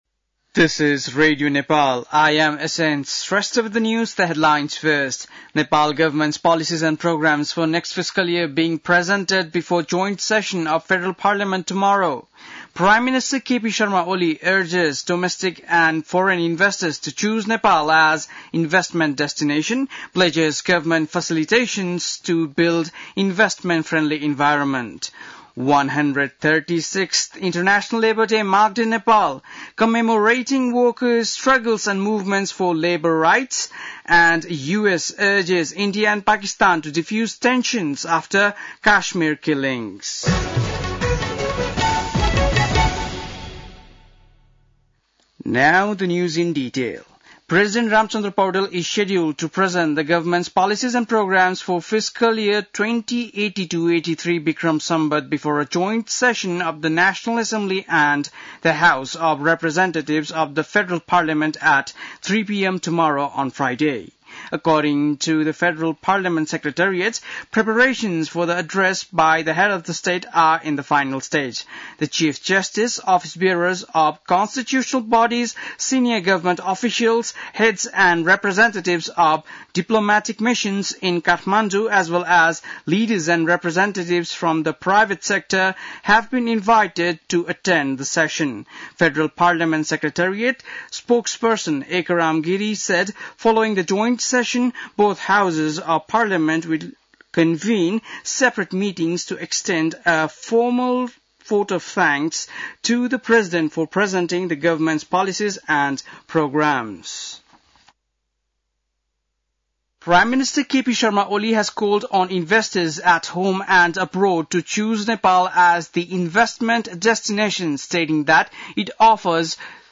बेलुकी ८ बजेको अङ्ग्रेजी समाचार : १८ वैशाख , २०८२
8-pm-english-news.mp3